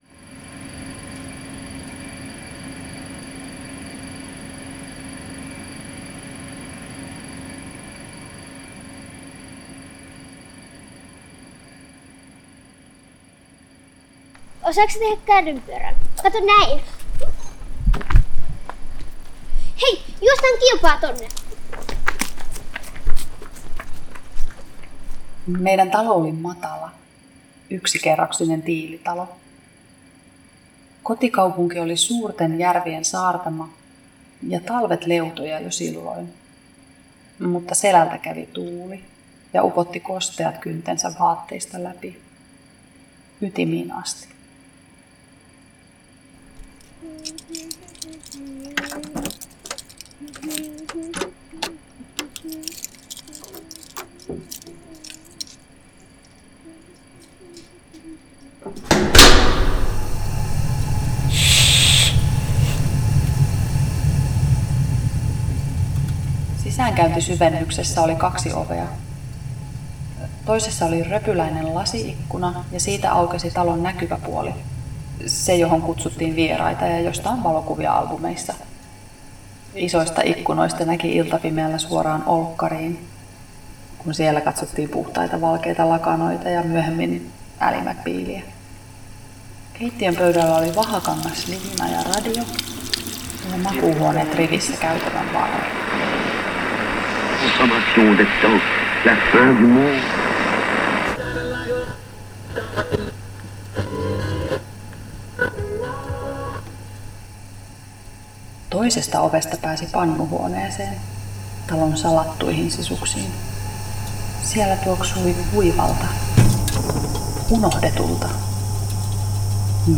Dive into the oral histories with the audio play “pannuhuone”!